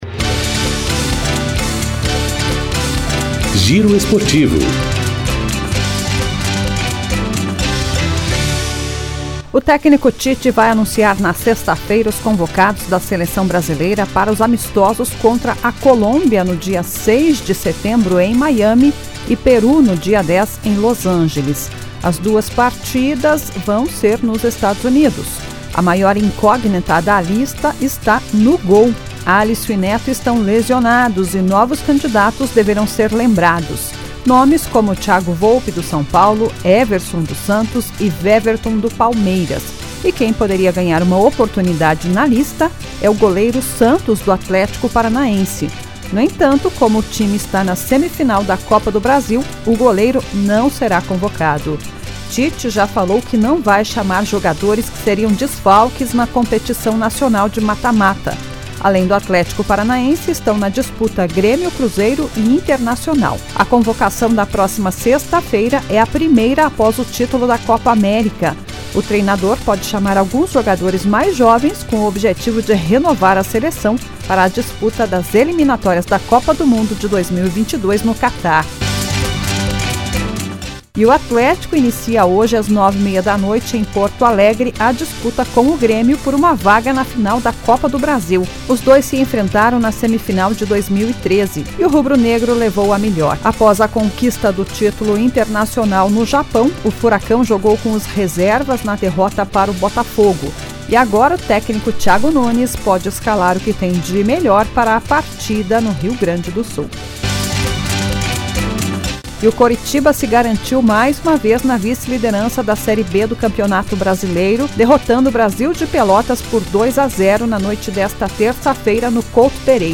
Giro Esportivo COM TRILHA